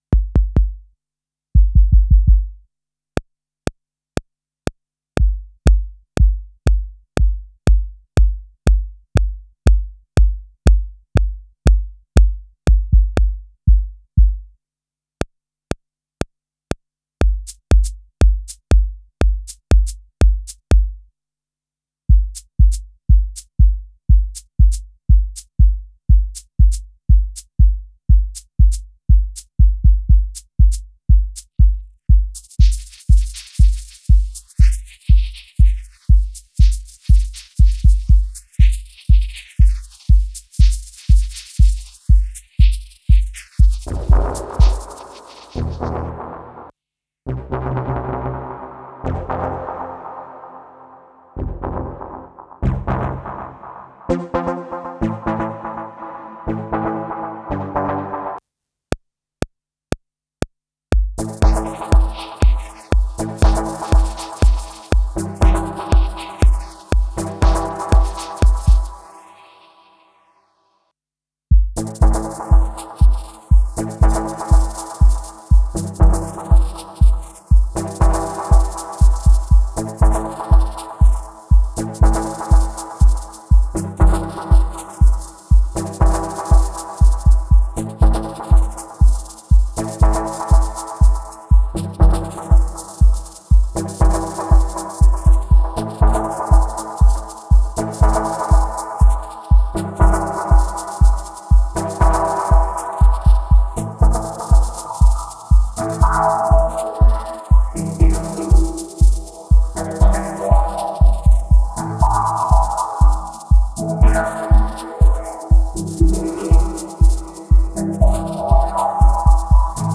Rhythm & Sound Dub Stab
Sketching out ideas (not necessarily good ones…) in realtime on the MPC One.